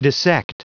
Prononciation du mot dissect en anglais (fichier audio)
Prononciation du mot : dissect